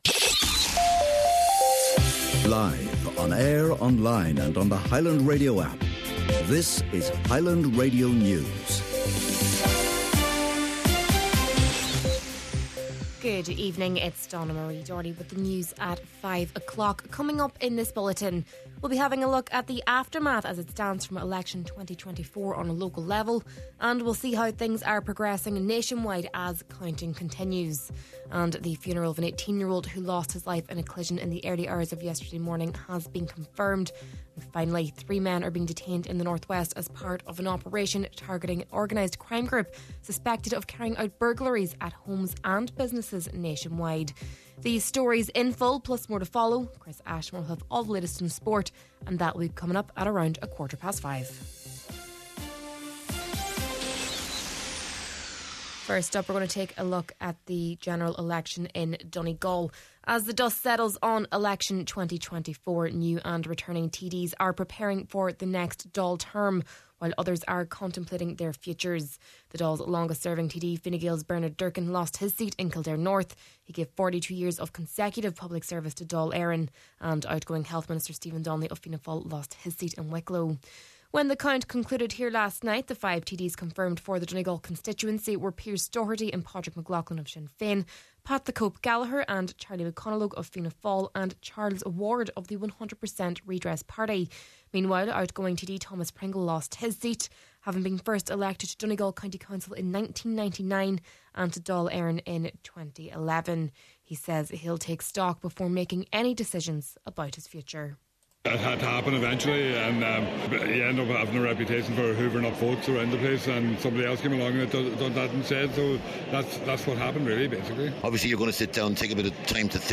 Main Evening News, Sport and Obituaries – Monday, December 2nd